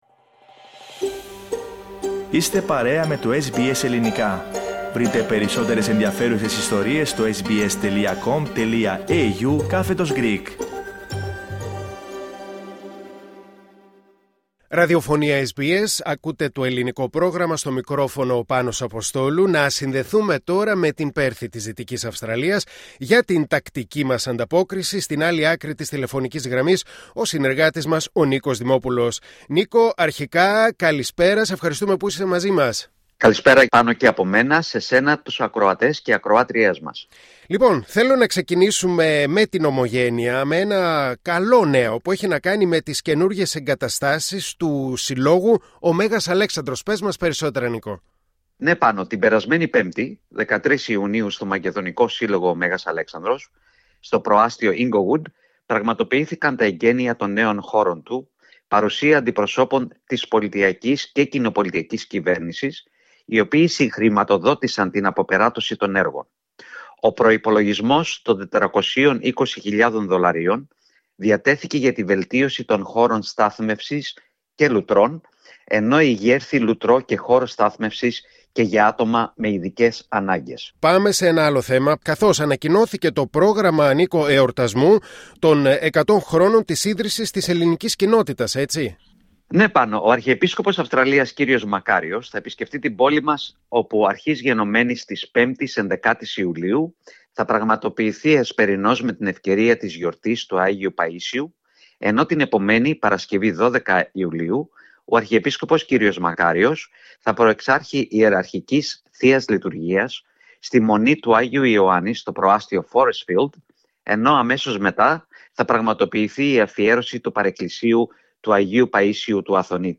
Ακούστε την ανταπόκριση από την Πέρθη της Δυτικής Αυστραλίας